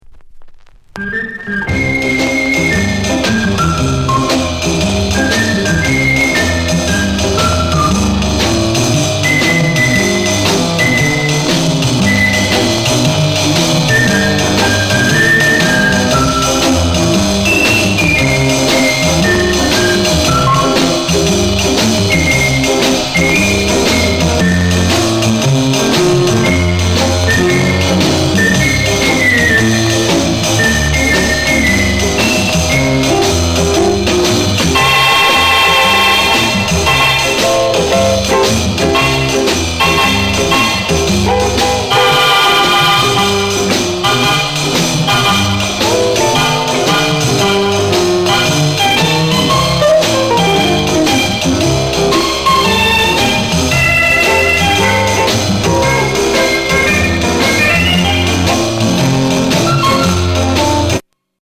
SHUFFLE SKA INST